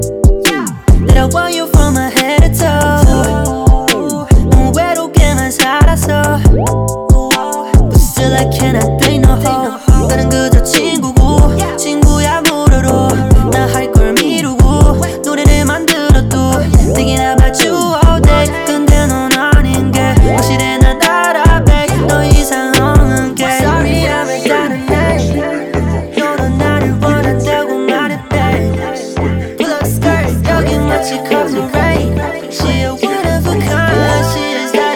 Hip-Hop Rap Pop K-Pop
Жанр: Хип-Хоп / Рэп / Поп музыка